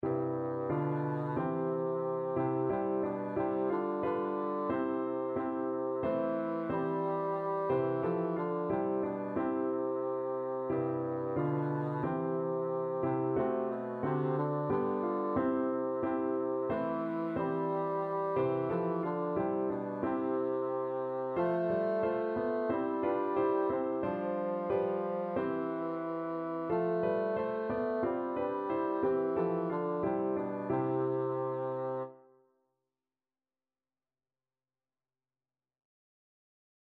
Christmas Christmas Bassoon Sheet Music Once in Royal David's City
Bassoon
C major (Sounding Pitch) (View more C major Music for Bassoon )
G3-A4
4/4 (View more 4/4 Music)